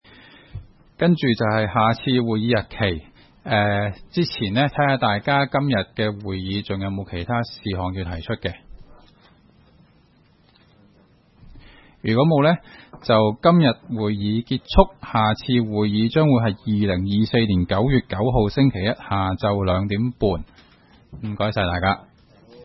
區議會大會的錄音記錄
屯門區議會會議室